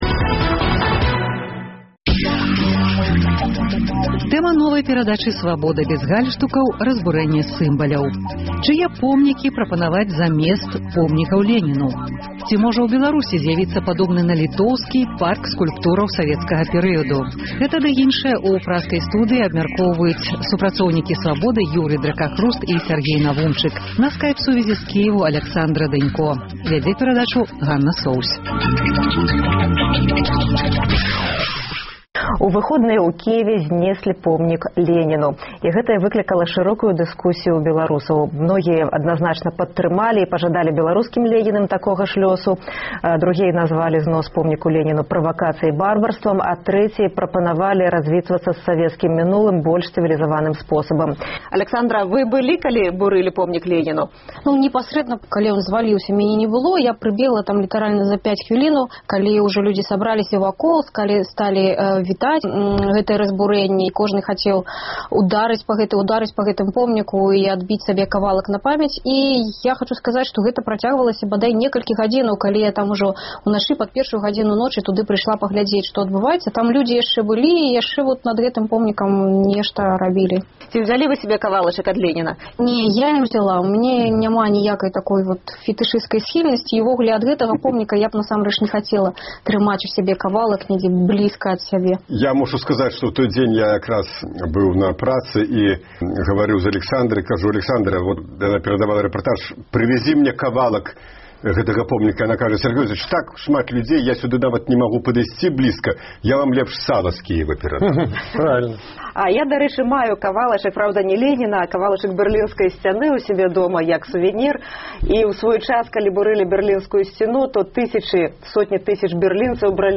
на скайп-сувязі з Кіеву